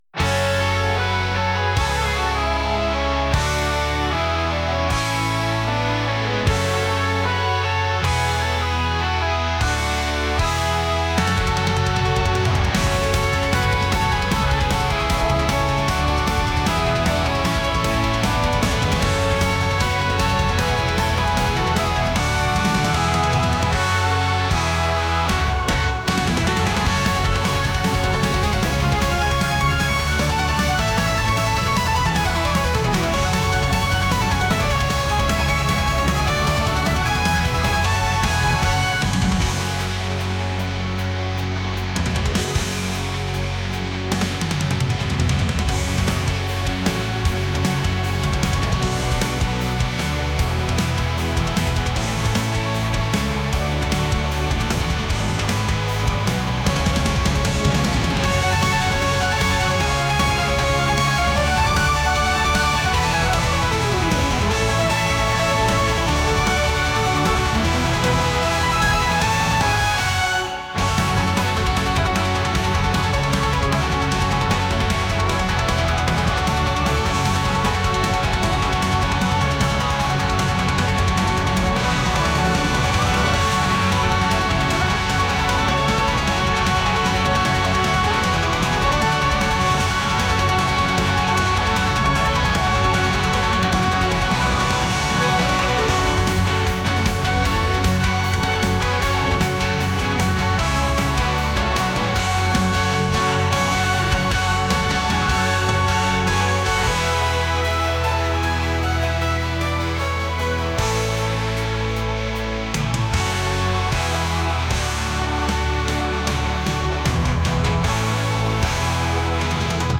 rock | country | folk